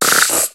Cri de Bargantua dans Pokémon HOME.